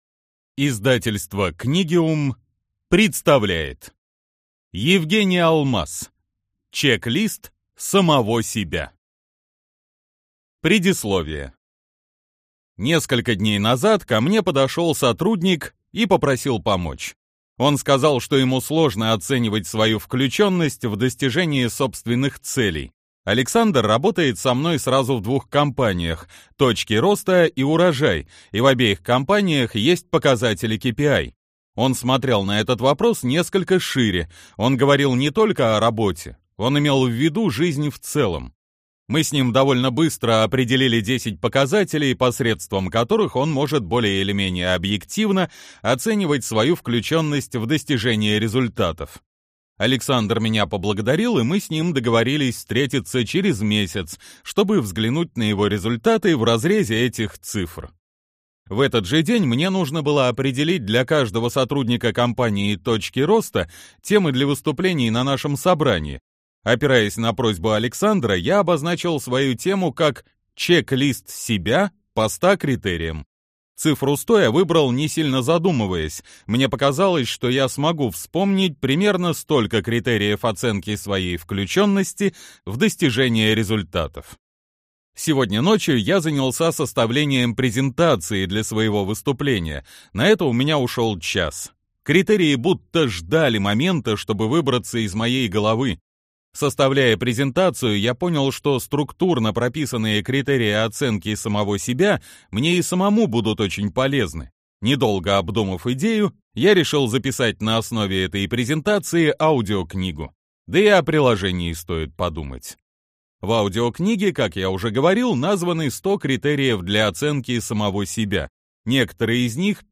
Аудиокнига Чек-лист самого себя | Библиотека аудиокниг